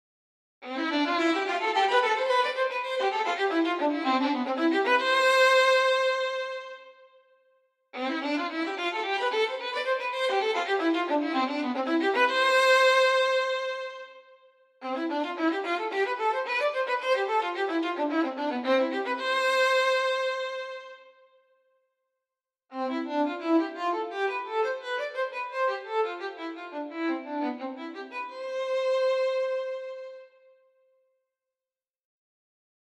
Part 1a = legato one slur (no sequencer picture)
Part 2 = legato simulated with sfz-stac / sfz - stac / sfz - stac ...
Part 3 = legato simulated with 0,3s - stac / 0,3s -stac / 0,3s ...